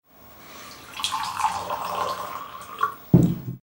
Servir agua en una jarra
Sonidos: Agua
Sonidos: Acciones humanas
Sonidos: Hogar